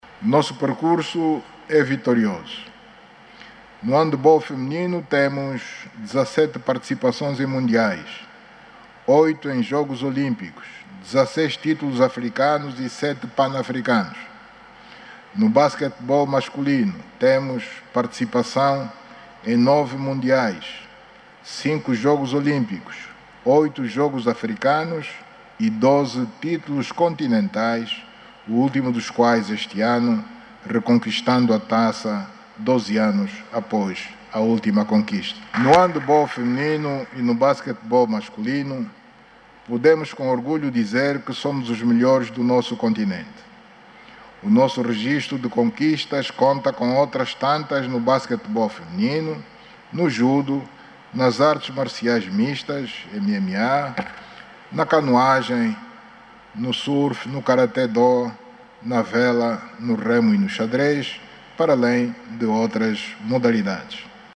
O Presidente da República, João Lourenço, enaltece a conquista do desporto no habitual discurso de abertura do ano parlamentar. O  número um  do Executivo angolano, enumerou o percurso de sucesso das modalidades desportivas.